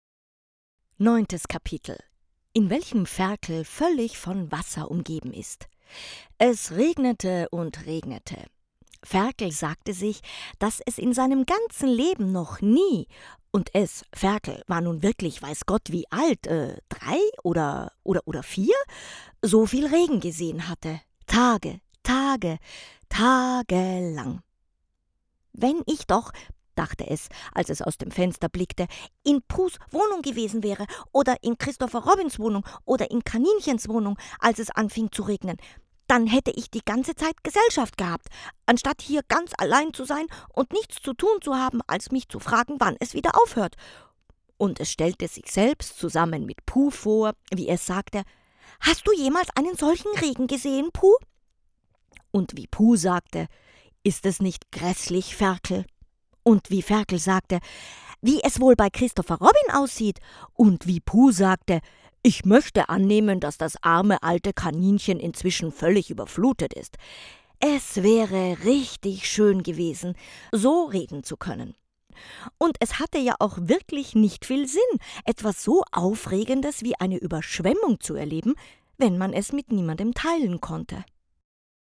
sehr vielseitige, erfahrene Theater und Filmschauspielerin deutsch österreichisches deutsch
wienerisch
Sprechprobe: Sonstiges (Muttersprache):
very experienced actress and voice actress stage-tv-movie-microfone
Kinderhörbuch.mp3